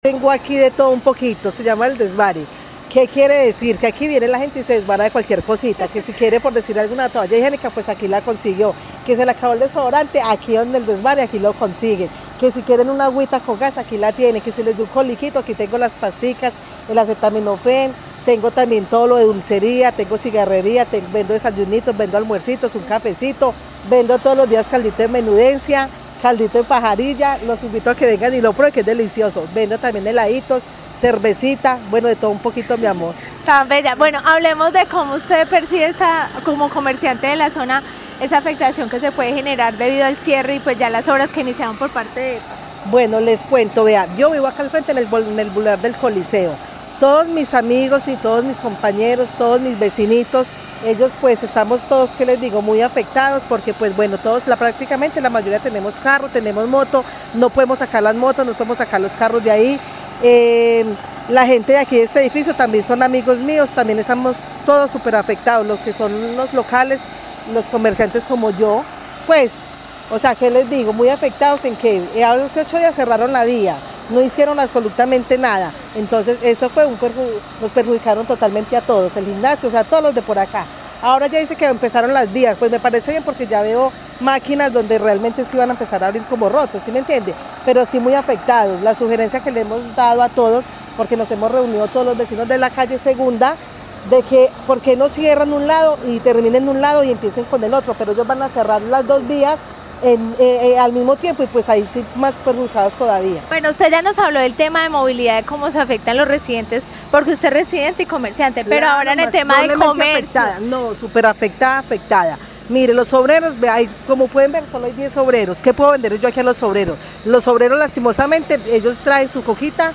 comerciante del sector